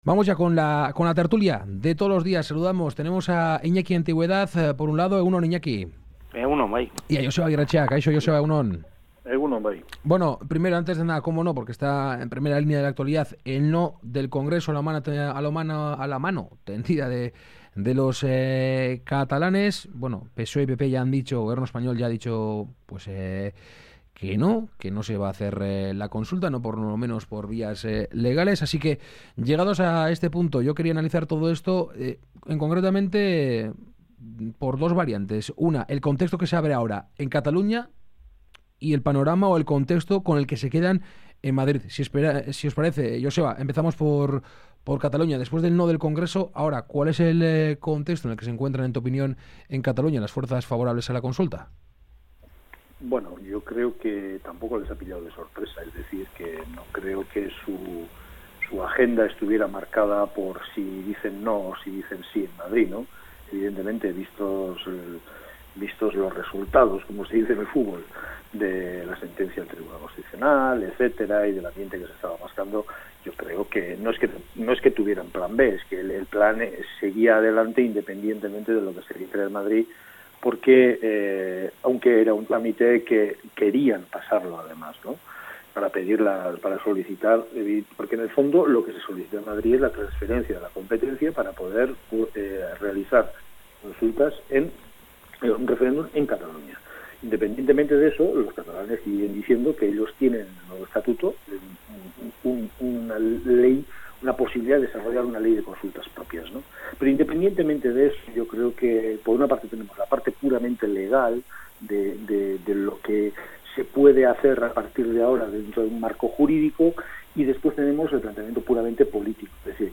La tertulia de Kale Gorrian
Charlamos y debatimos sobre algunas de las noticias mas comentadas de la semana con nuestros colaboradores habituales. Hoy, participan en la tertulia Joseba Agirretxea e Iñaki Antiguedad.